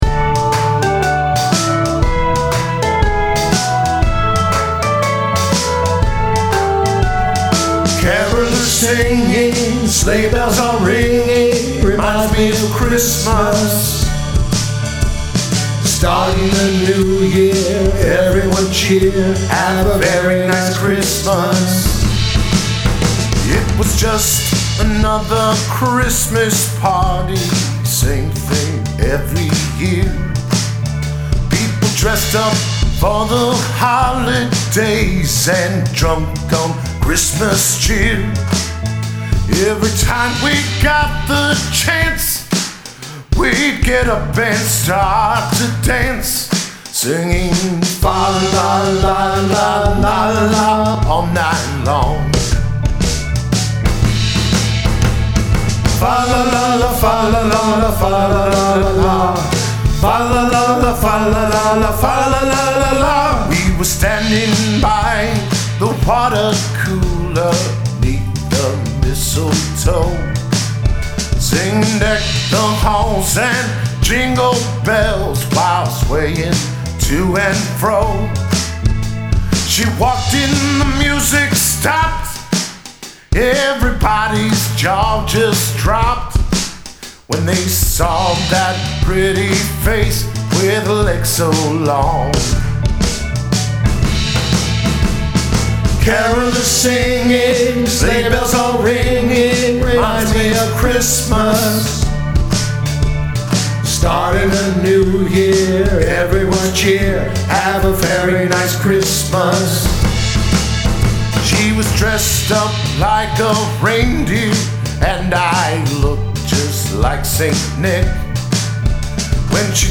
Christmas song